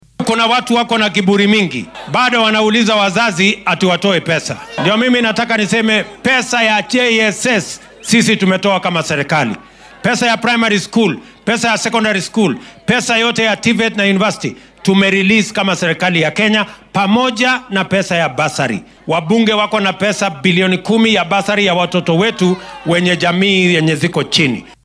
William Ruto ayaa xilli uu ku sugnaa ismaamulka Busia sheegay in dowladdu ay bixisay lacag dhan 62 bilyan oo shilin oo loogu talagalay dhammaan xarumaha tacliinta sida dugsiyada hoose dhexe, dugsiyada sare , machadyada farsamada iyo jaamacadaha.